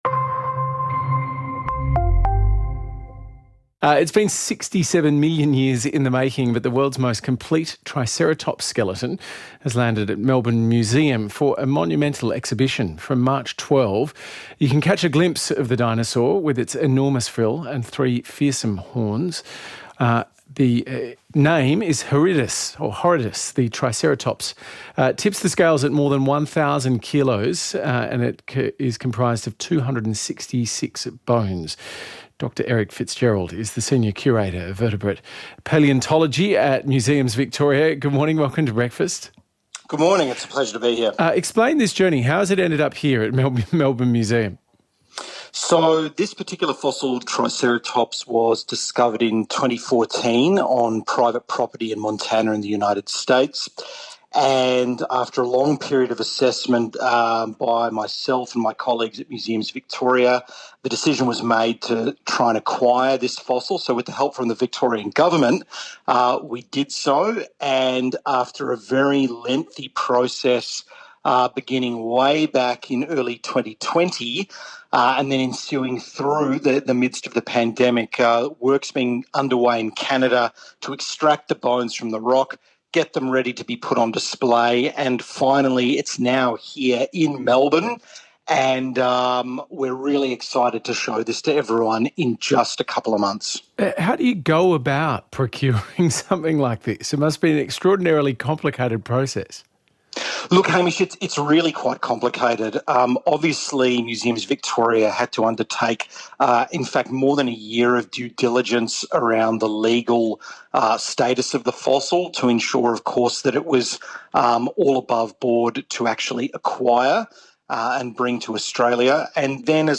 On RN Breakfast with Hamish McDonald